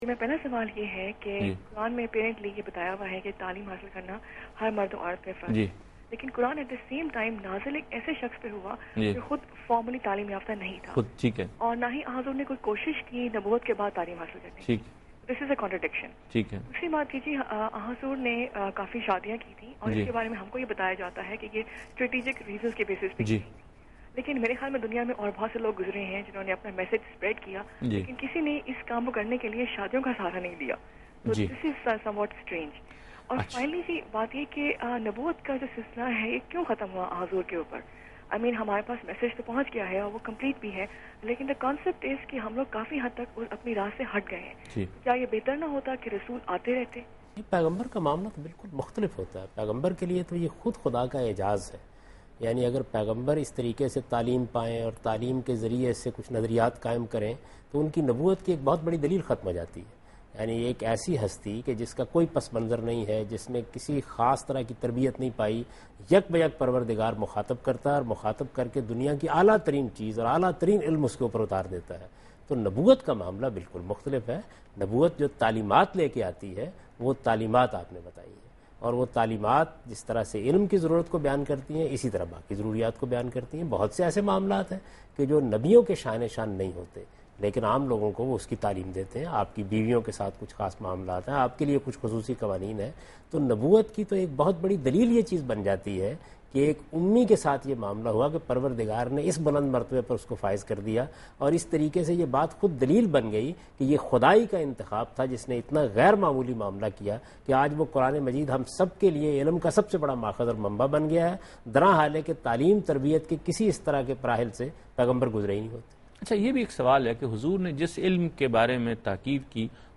TV Programs
Answer to a Question by Javed Ahmad Ghamidi during a talk show "Deen o Danish" on Duny News TV
دنیا نیوز کے پروگرام دین و دانش میں جاوید احمد غامدی ”نبوت اور تعلیم“ سے متعلق ایک سوال کا جواب دے رہے ہیں